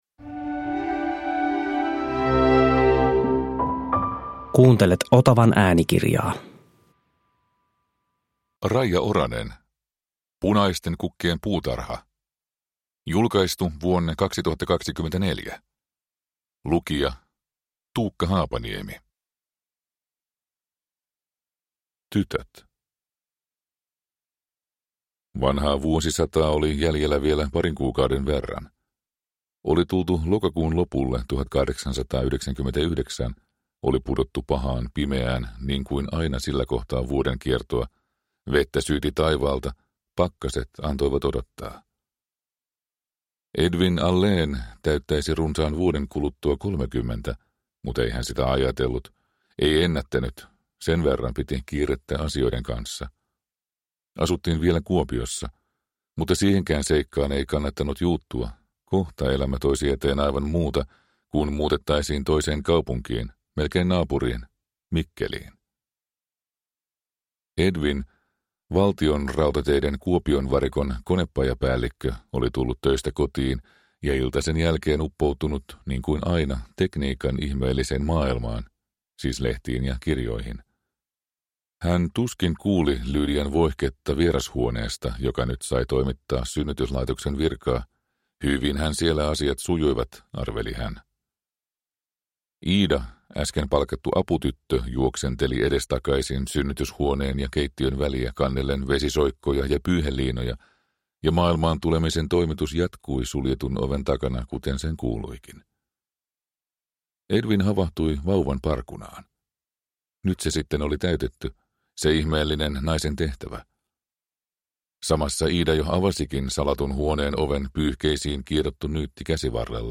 Punaisten kukkien puutarha (ljudbok) av Raija Oranen